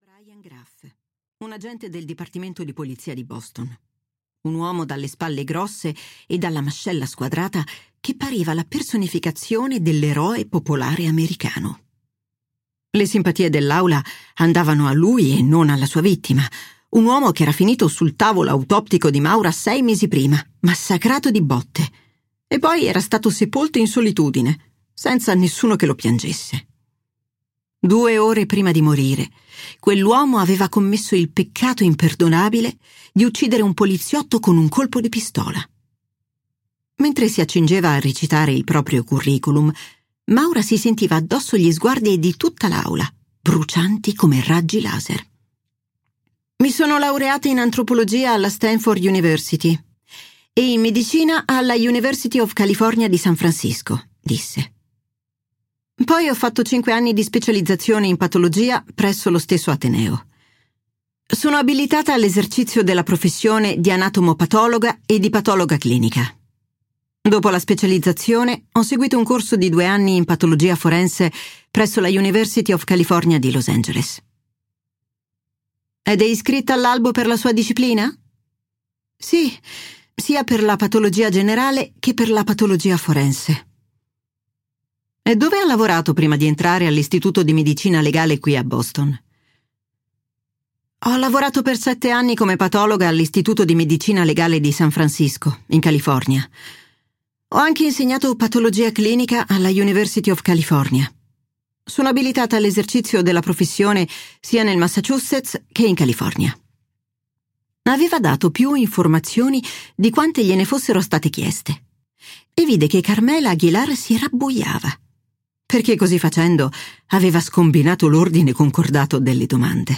"La Fenice rossa" di Tess Gerritsen - Audiolibro digitale - AUDIOLIBRI LIQUIDI - Il Libraio